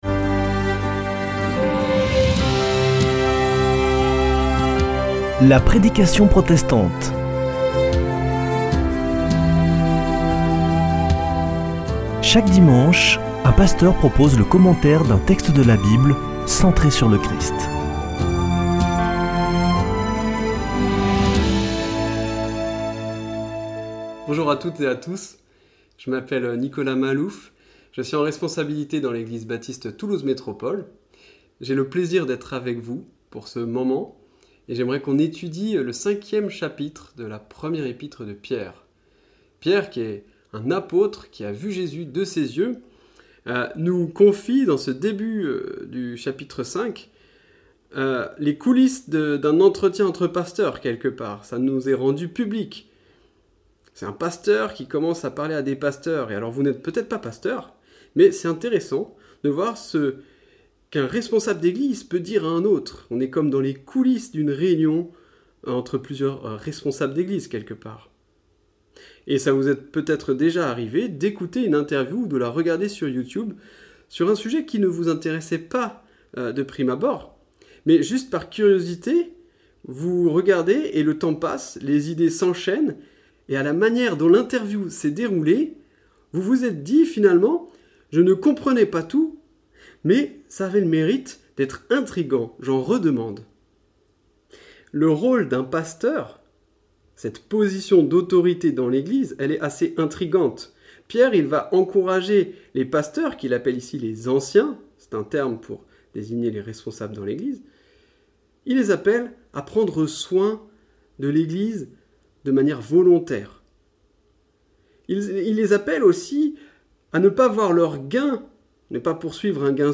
Accueil \ Emissions \ Foi \ Formation \ La prédication protestante \ S'humilier pour être élevé ?